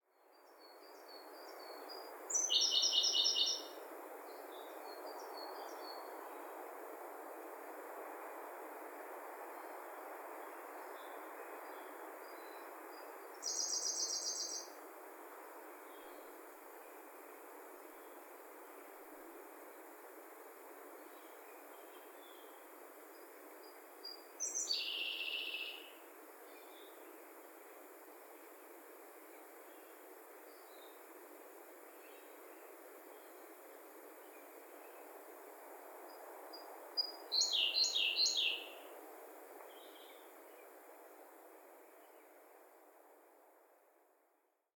２　コルリ（小瑠璃）Siberian Blue Robin　全長：14㎝
コマドリに似たさえずりだが「チッチッチッ」と前奏が入る。その他の声は「ゲジゲジゲジ」と聞こえる事がある。
【録音②】　2024年5月　栃木県矢板市